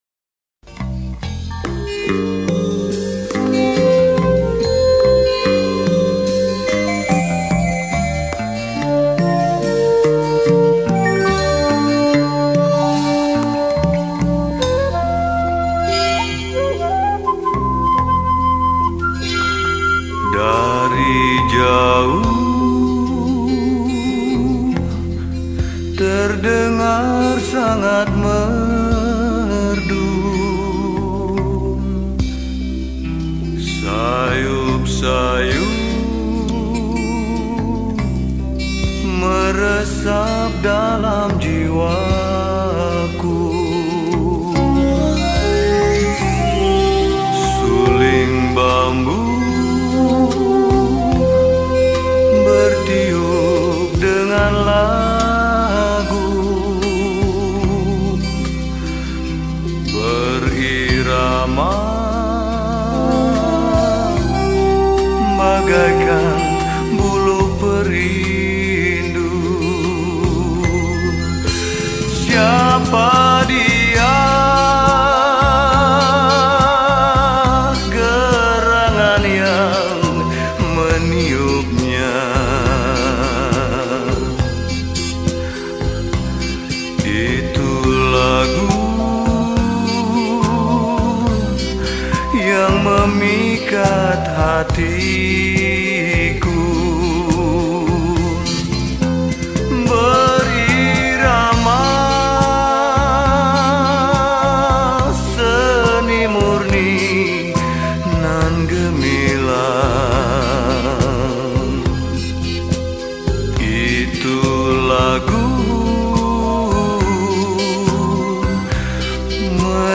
Indonesian Song